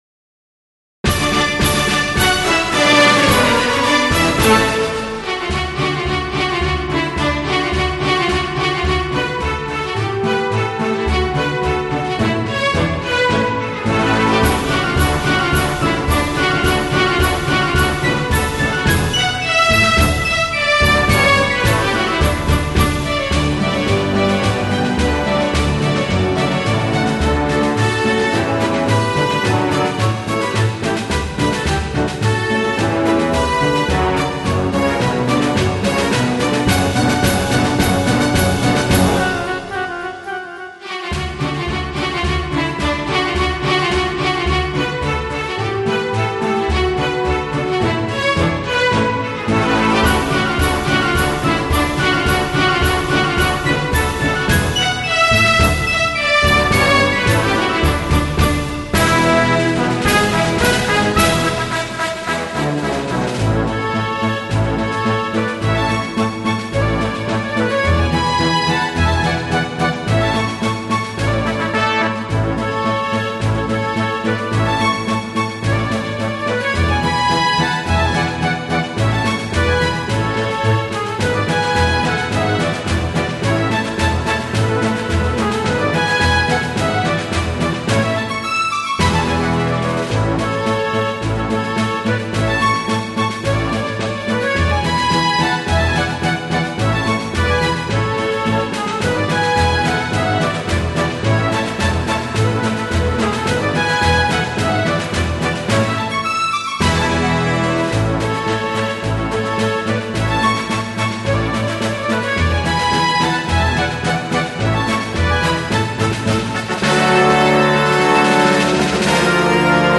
进行曲是一种以步伐节奏写成的乐曲。